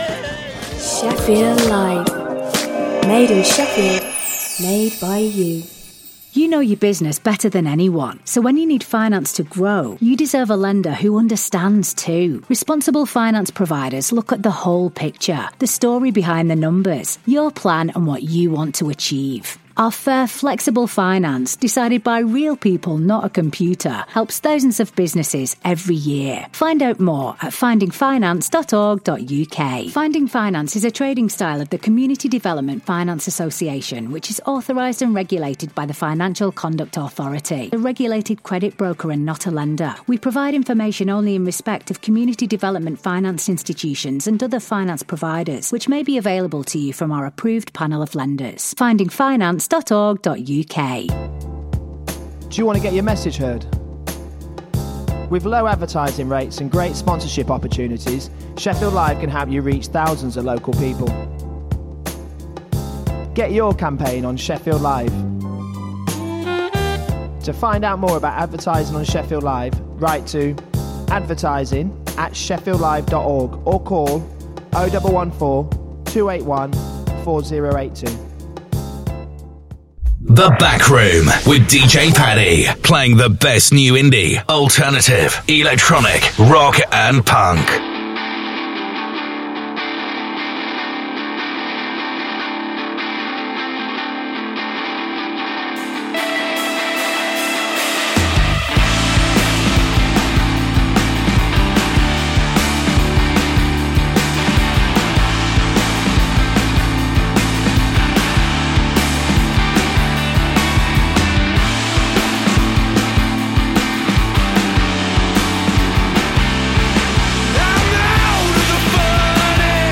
playing the best in new indie , electro, EDM, and punk